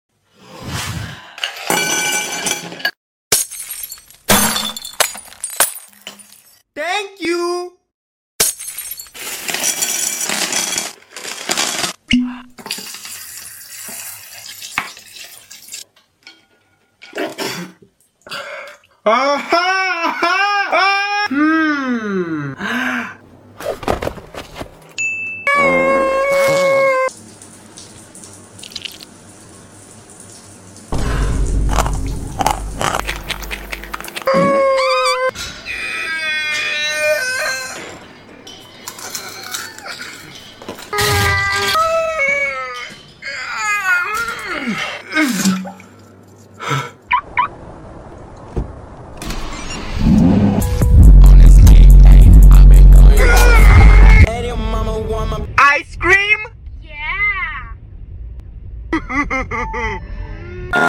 My Morning Routine… (Calm ASMR)